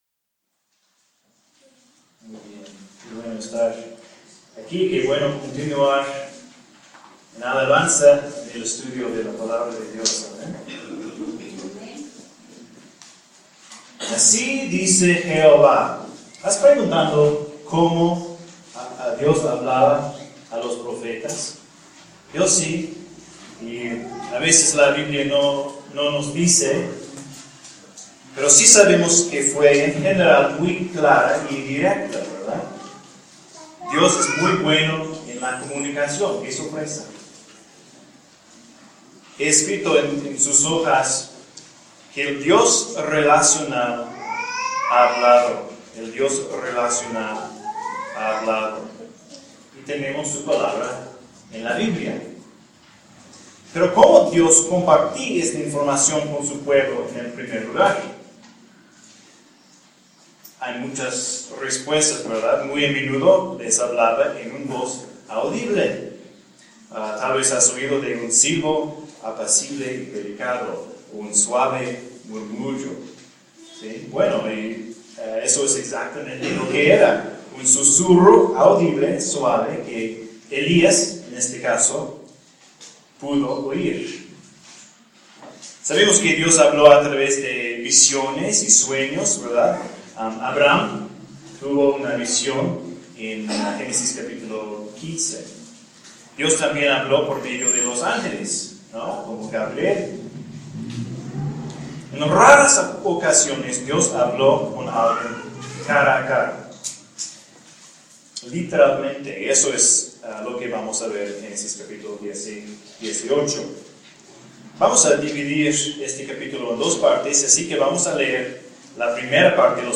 Un sermón de Génesis 18.